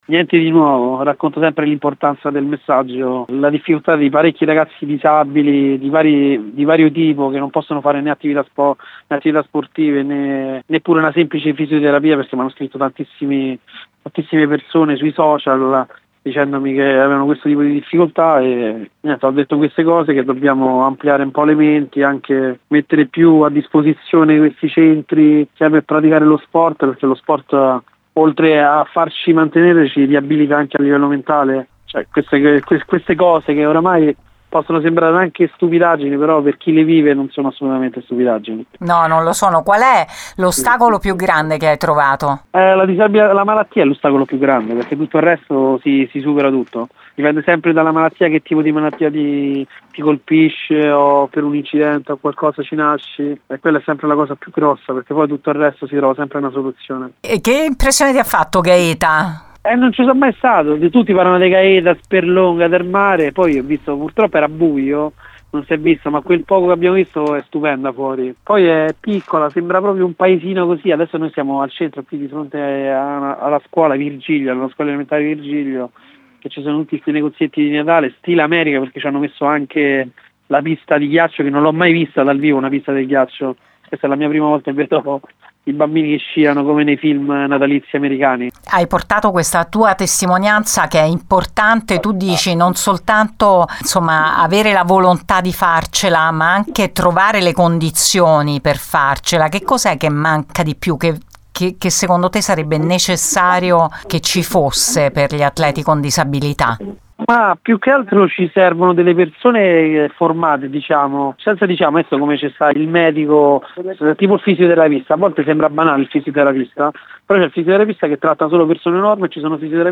Ne abbiamo parlato con lui per Gr Latina